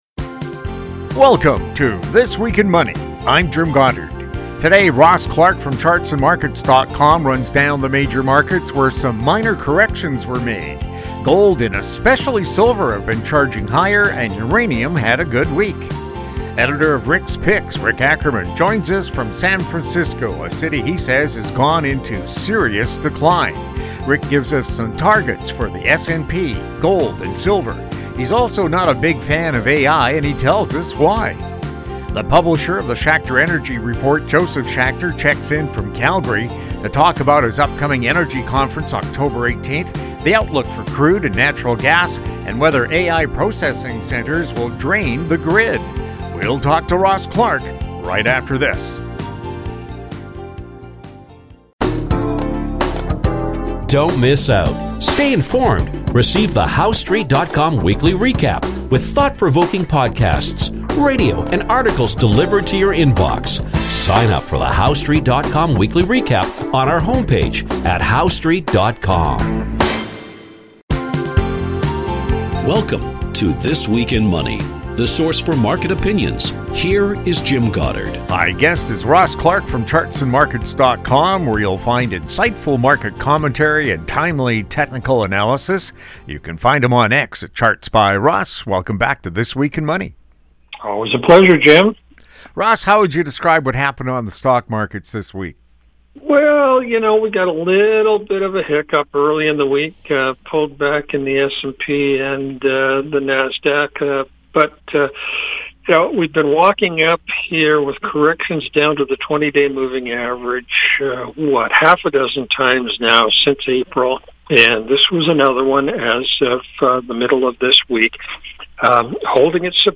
New shows air Saturdays on Internet Radio.